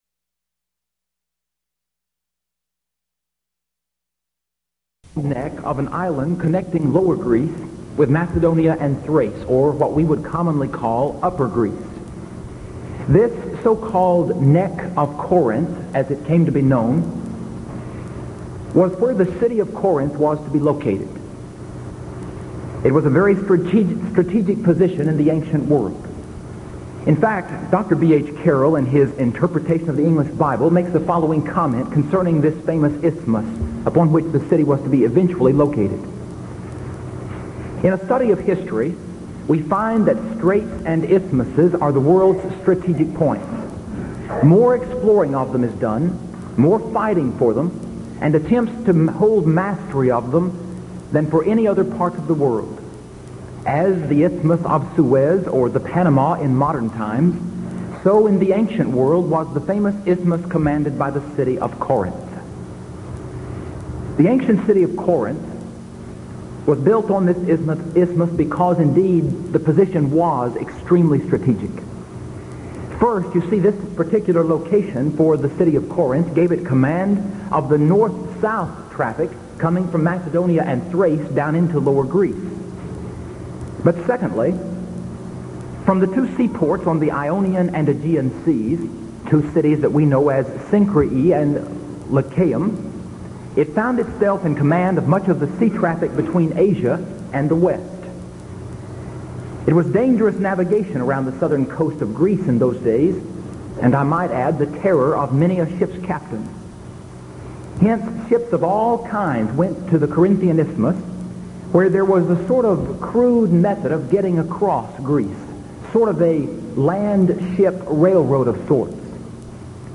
Series: Denton Lectures Event: 1982 Denton Lectures